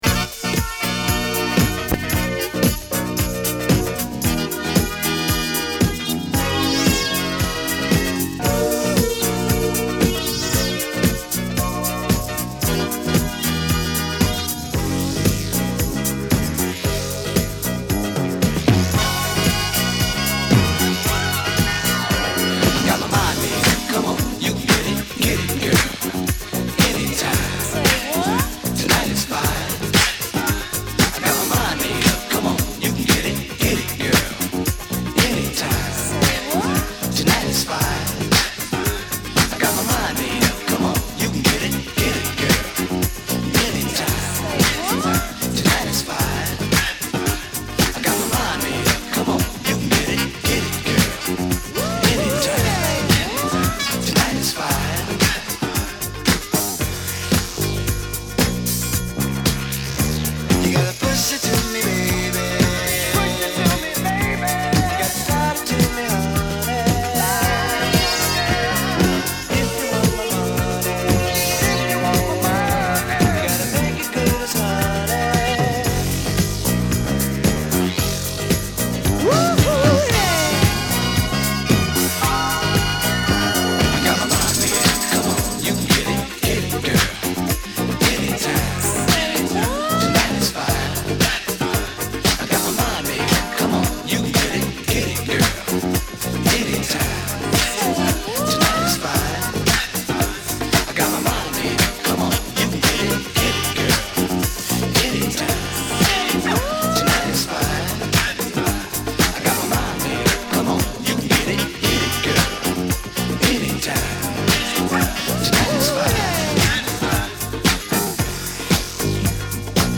mixed as a continuous groove